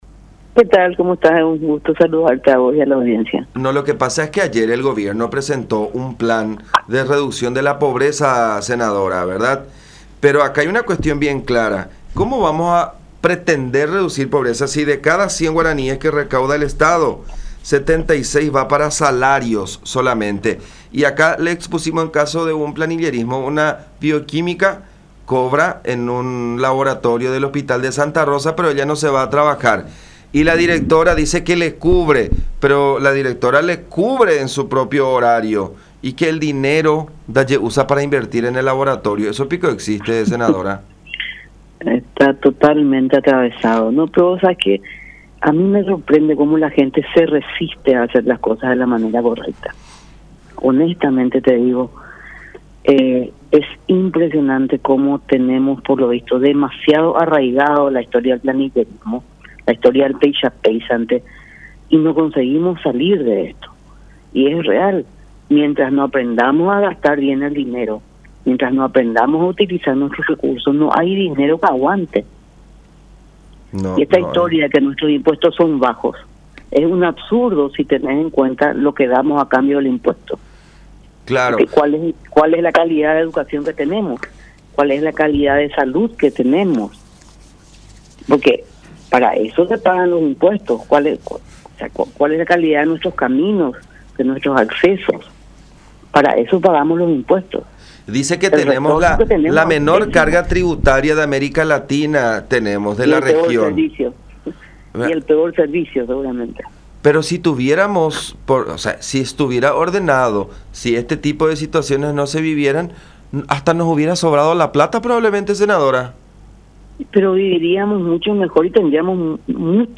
Ellos golpearon el vidrio y nuestro vehículo queriendo que nos bajemos”, dijo Arrúa en diálogo con La Unión, afirmando que se trataba de patoteros que se alejaron luego de que los advirtiera de que llamaría a la Policía.